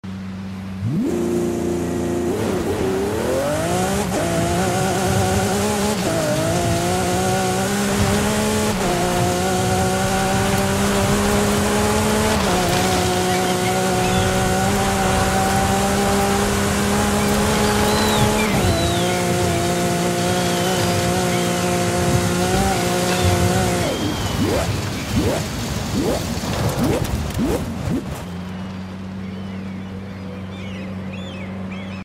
2020 Lamborghini SC20 Off Road Launch sound effects free download
2020 Lamborghini SC20 Off-Road Launch Control - Forza Horizon 5